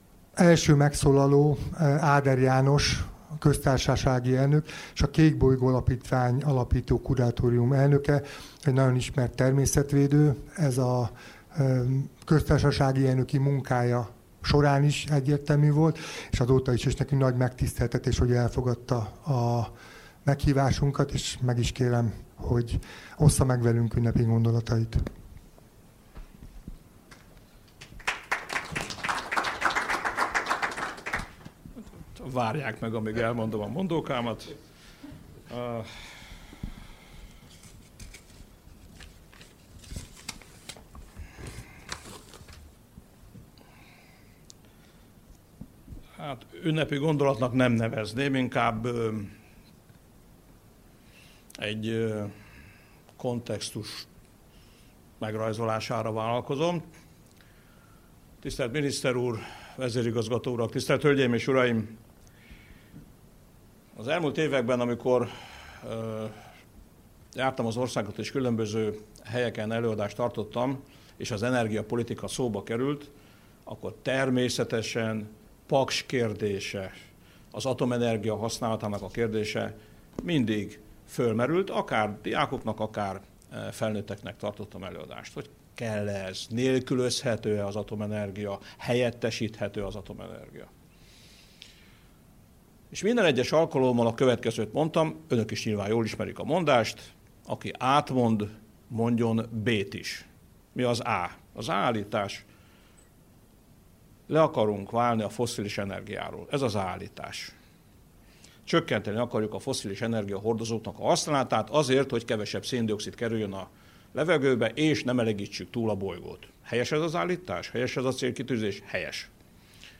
A Nemzetközi Energia Ügynökség szerint az atomenergiára a párizsi klímacélok eléréséhez is szükség van – mondta Áder János a Paksi Atomerőmű alapkőletételének 50. évfordulója alkalmából tartott sajtóeseményen.